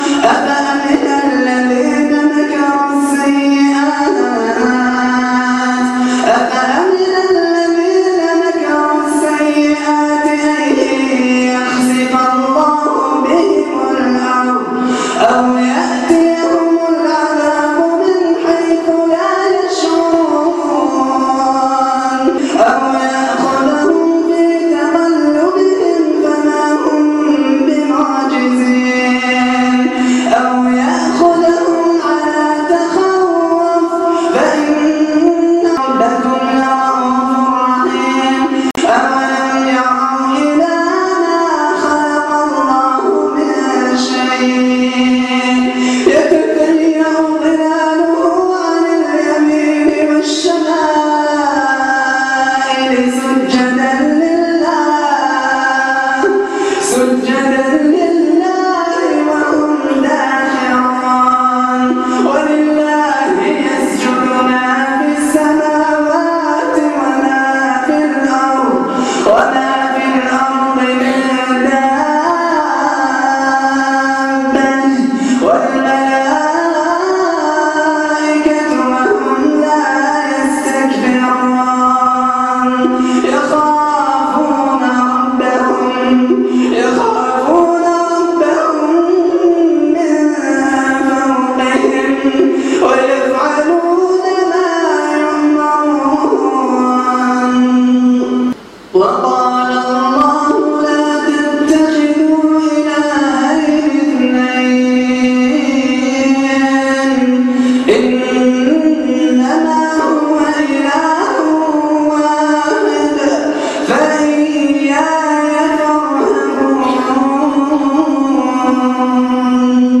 تلاوة خاشعة و مؤثرة